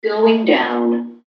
S – GOING DOWN (ELEVATOR VOICE)
S-GOING-DOWN-(ELEVATOR-VOICE).mp3